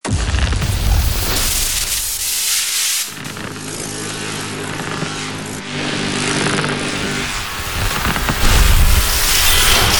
Звук коллапса электрической аномалии